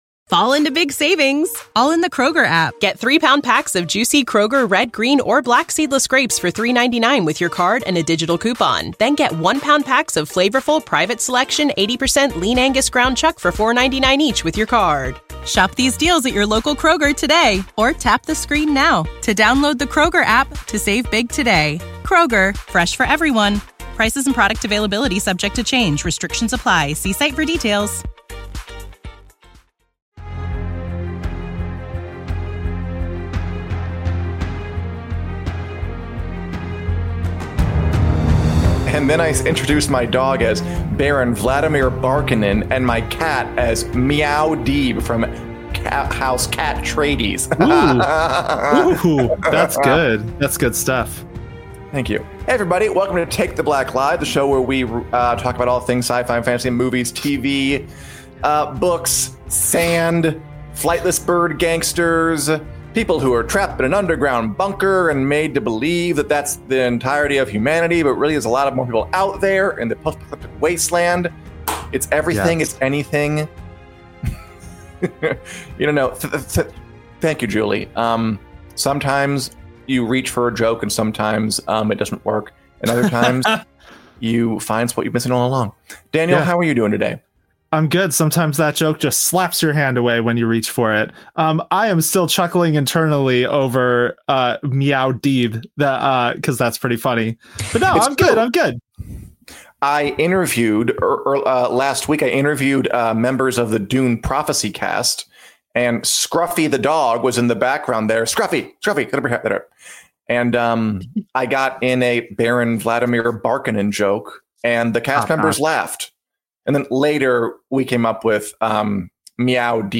This conversation was recorded at the Spirit of Independence Film Festival in Sheffield alongside a bunch of ot…